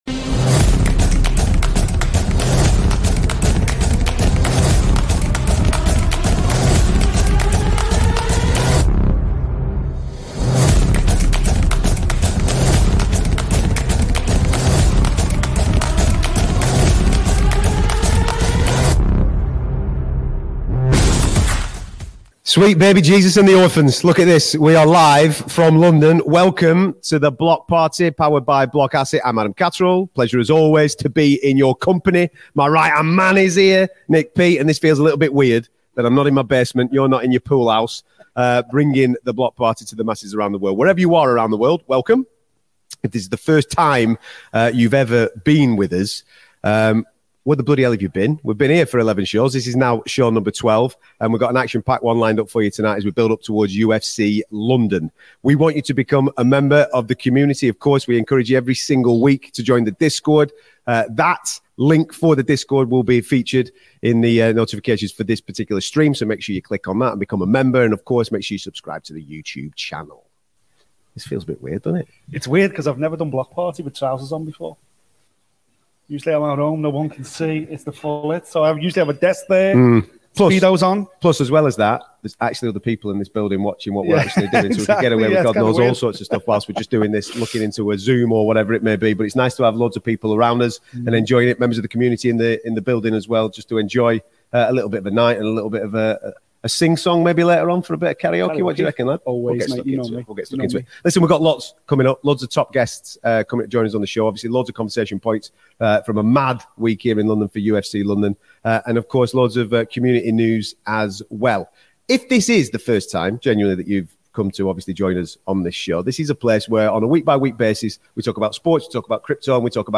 12: LIVE FROM LONDON: Curtis Blaydes, Guram Kutateladze and Arnold Allen
Tonight we are live from the capital ahead of UFC London on Saturday 23rd.